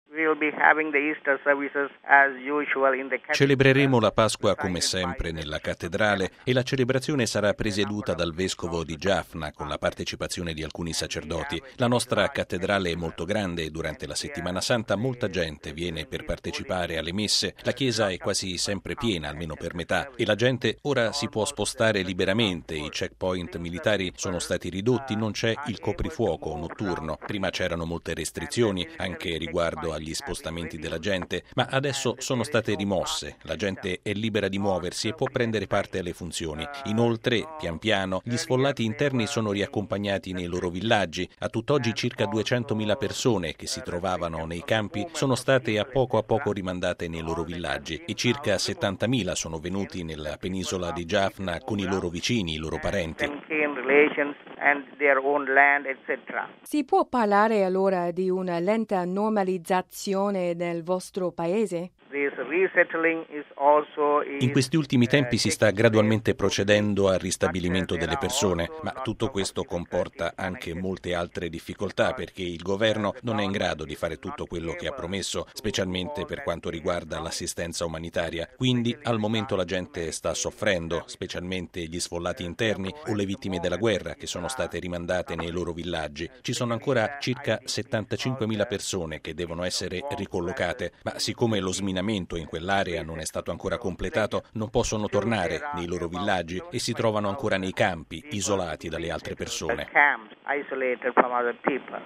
La Pasqua dei cristiani dello Sri Lanka, dopo i giorni della guerra. Intervista con il vescovo di Jaffna